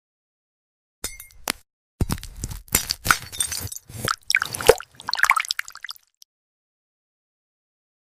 Cutting Glass Fruits Lemon ASMR Sound Effects Free Download
Cutting Glass Fruits Lemon ASMR sound effects free download